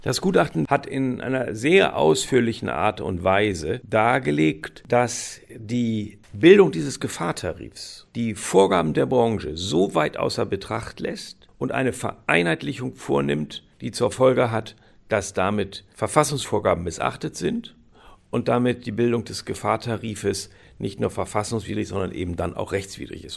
O-Töne + Magazin: Tarifänderung bremst kostengünstiges Bauen aus